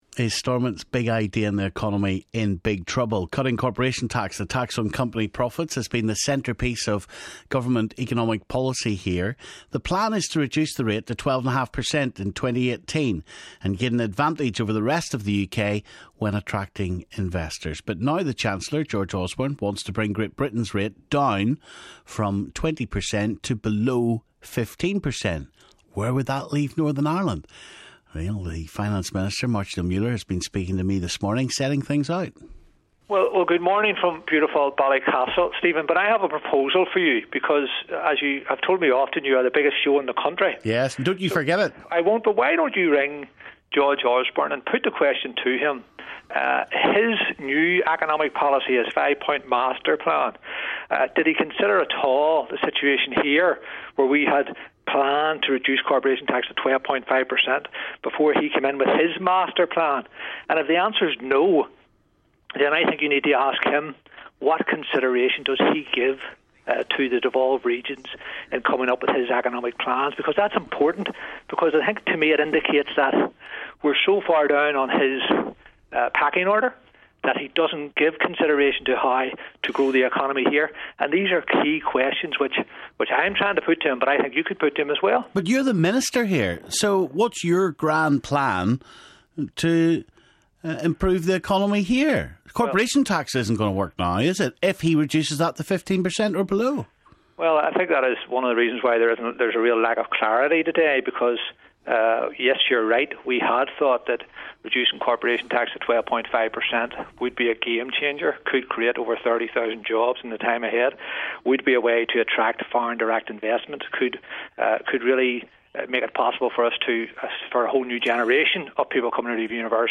Stephen speaks to the Finance Minister Máirtín Ó Muilleoir